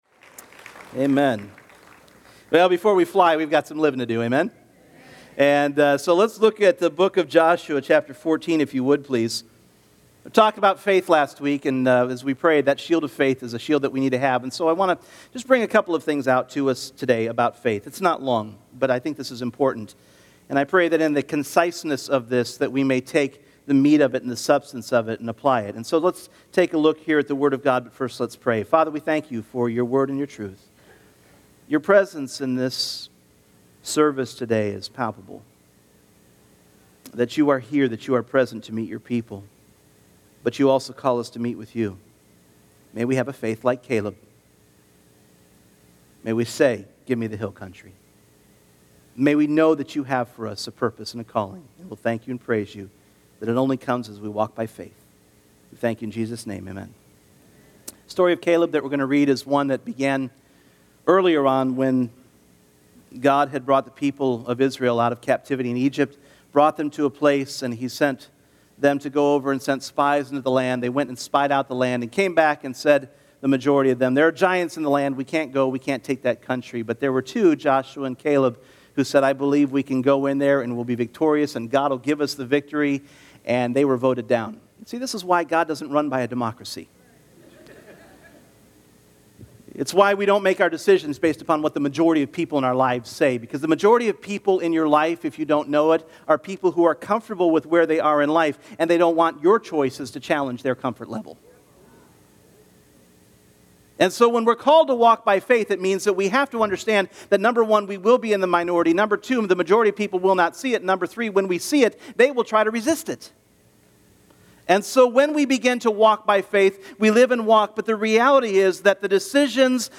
Faith Service Type: Sunday Morning Last week